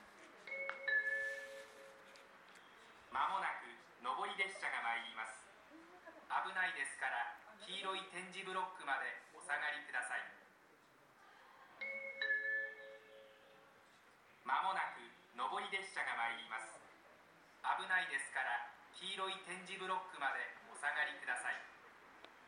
この駅では接近放送が設置されています。
１番線仙石線
接近放送普通　あおば通行き接近放送です。